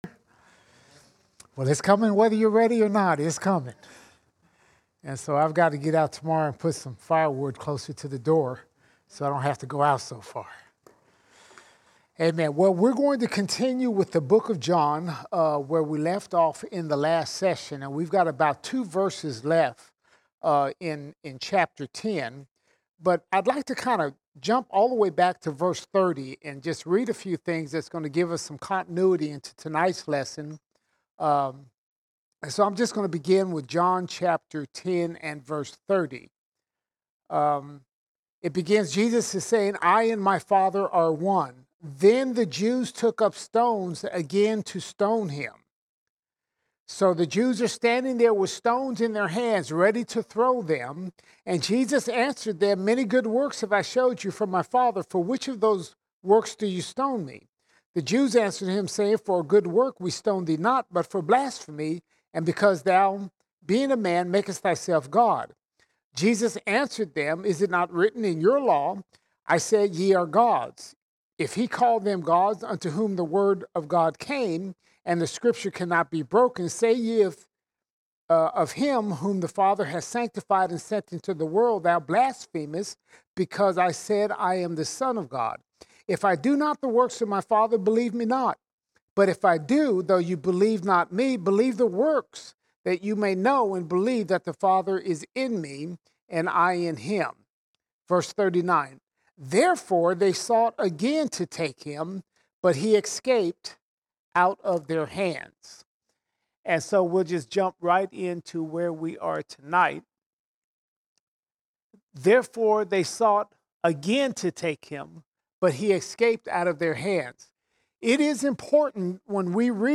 22 December 2022 Series: John All Sermons John 10:39 to 11:41 John 10:39 to 11:41 Jesus waits before He goes to awaken Lazarus from the dead.